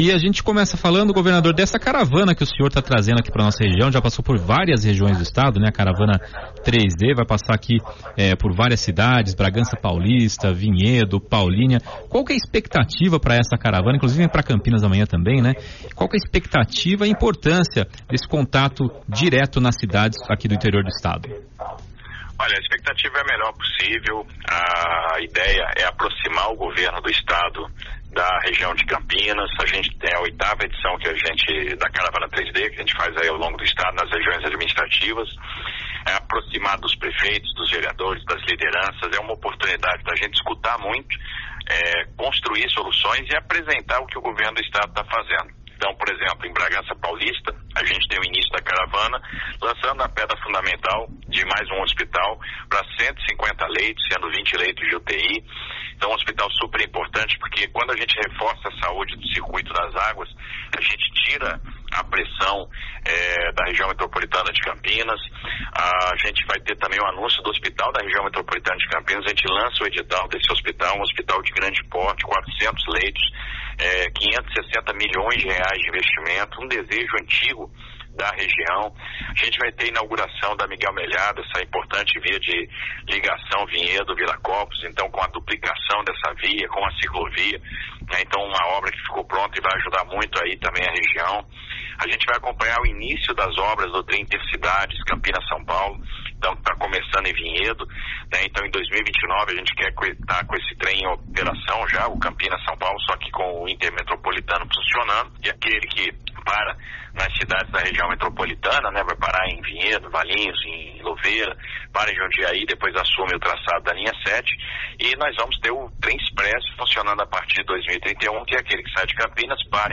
Em entrevista à Jovem Pan News Campinas, Tarcísio aponta possível redução de tarifas de pedágios e detalha agenda da Carav